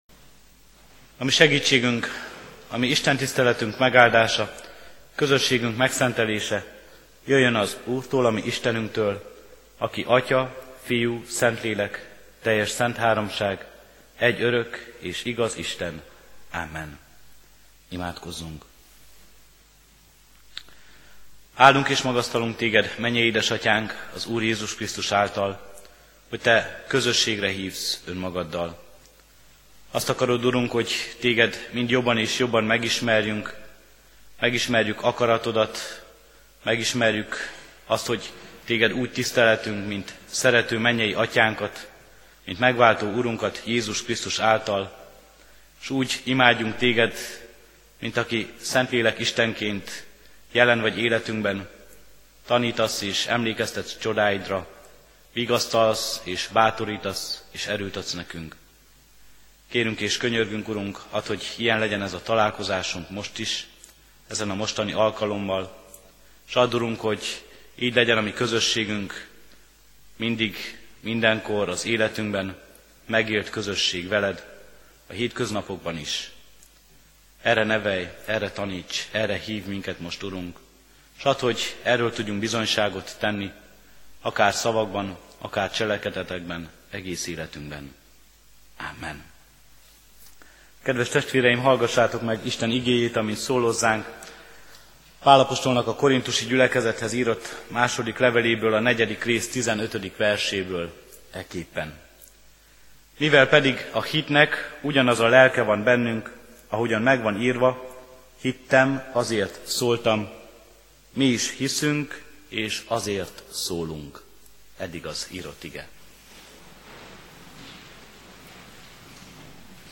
Igehirdetések Hitvallásunk